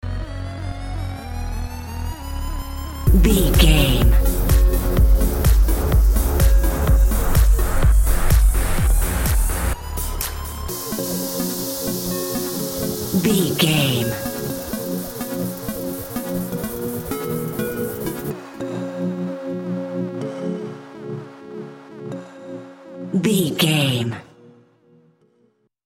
In-crescendo
Aeolian/Minor
F#
groovy
dreamy
smooth
futuristic
drum machine
synthesiser
house
electro dance
techno
trance
instrumentals
synth leads
synth bass
upbeat